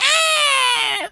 seagul_squawk_hurt_high2.wav